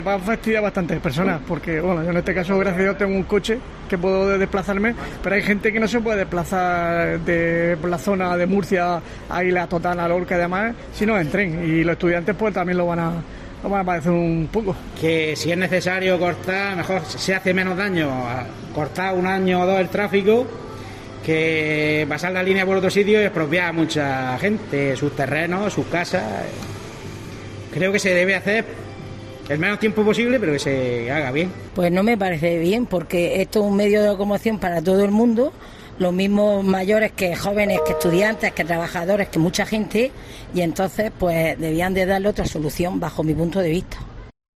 Vecinos de Lorca, opinan sobre el cierre de Cercanías.